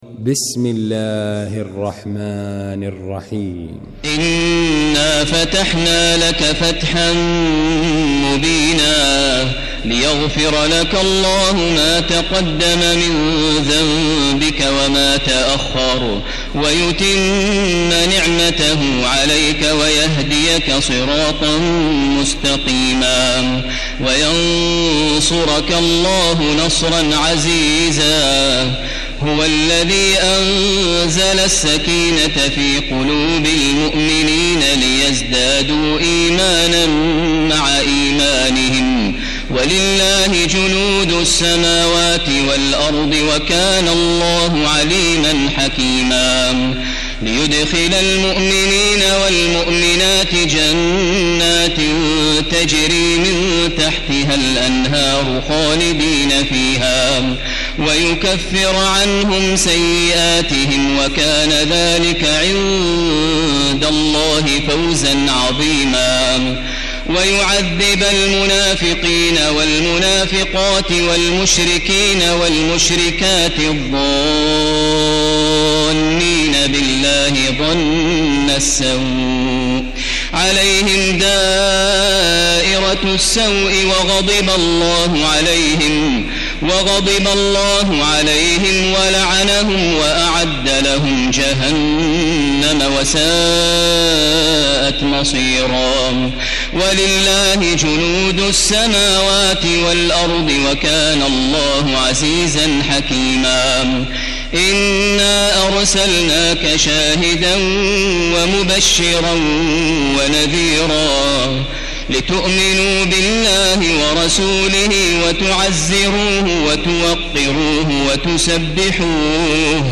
المكان: المسجد الحرام الشيخ: فضيلة الشيخ ماهر المعيقلي فضيلة الشيخ ماهر المعيقلي الفتح